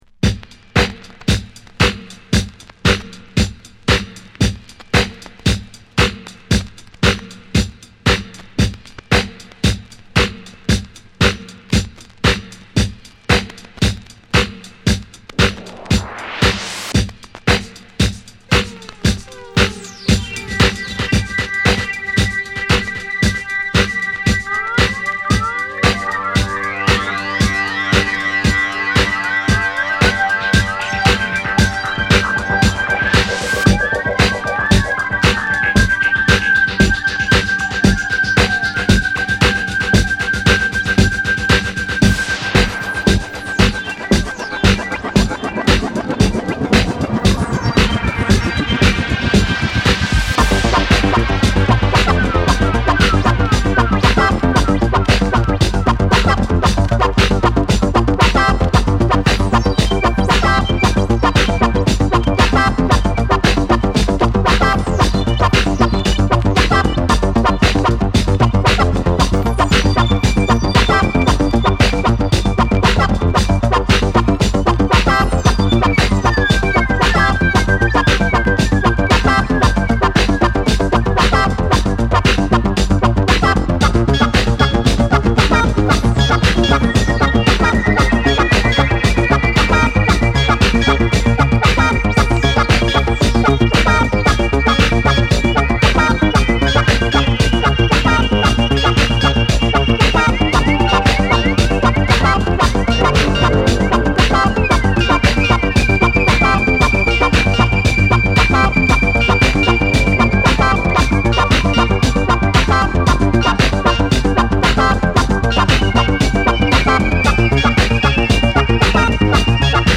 冒頭からドラッギーにシンセフレーズが飛び交うマッド・エレクトロ